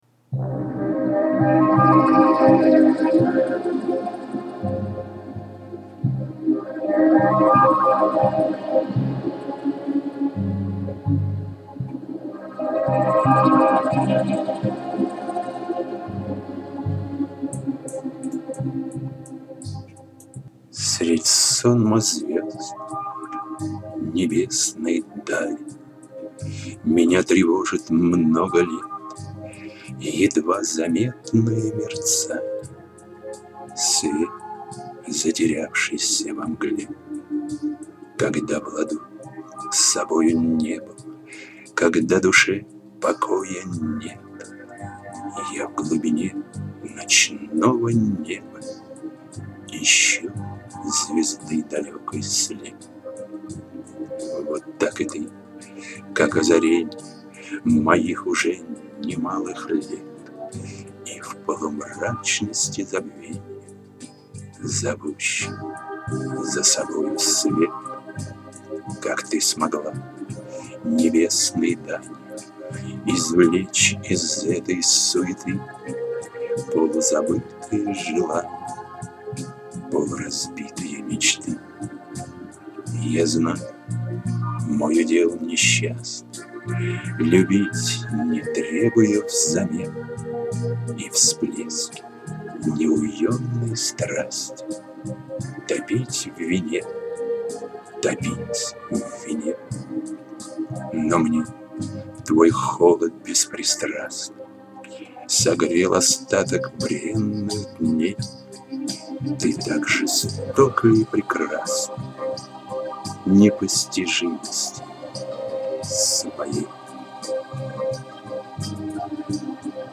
Отличное муз.сопровождение.
Но звук у вас по-прежнему "плавает"smile